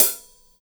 84 HAT.wav